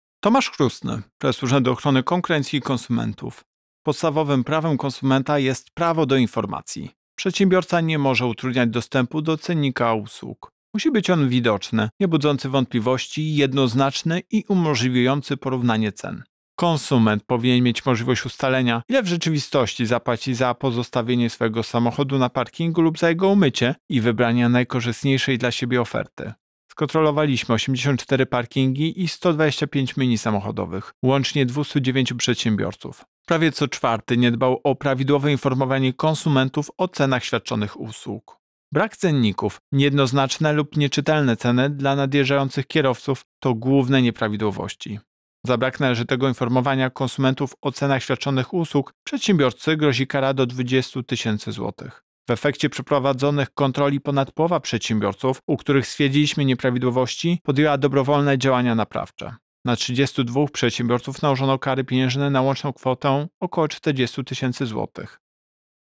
Wypowiedź Prezesa UOKiK Tomasza Chróstnego Według danych zgromadzonych przez Inspekcję Handlową blisko 23 proc. sprawdzonych przedsiębiorców nie dba o prawidłowe informowanie konsumentów o cenach świadczonych przez siebie usług.